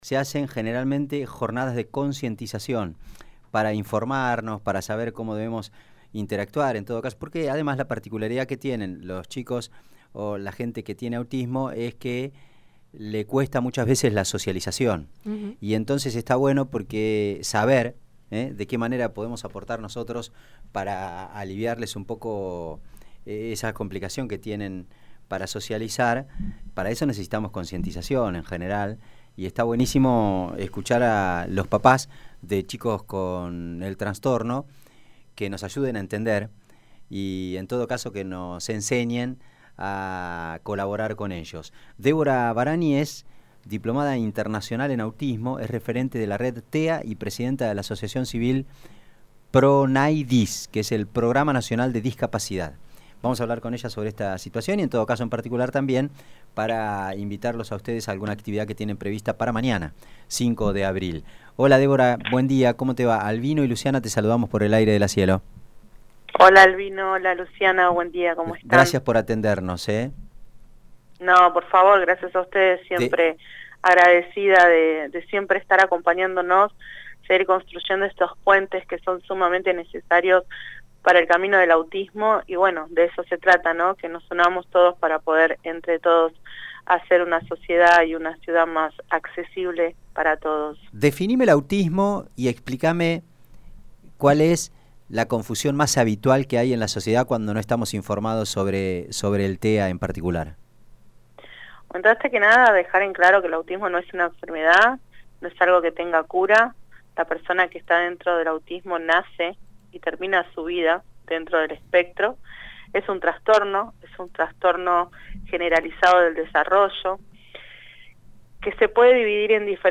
En dialogo